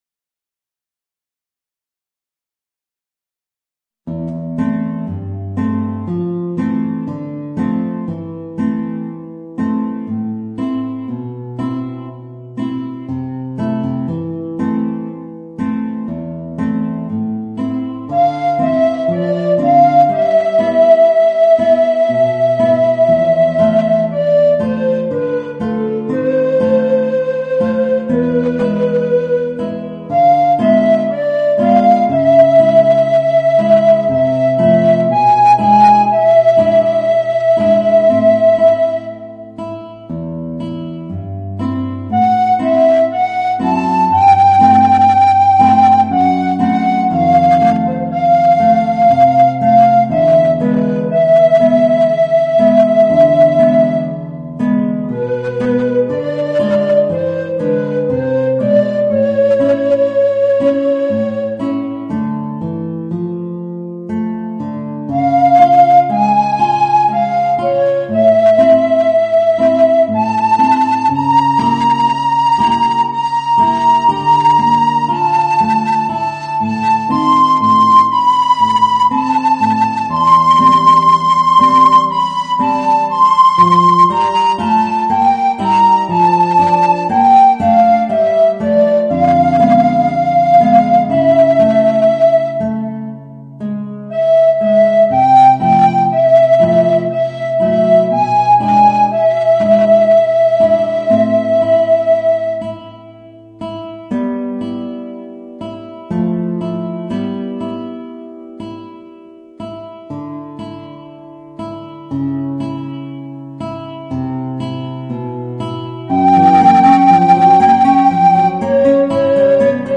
Voicing: Guitar and Alto Recorder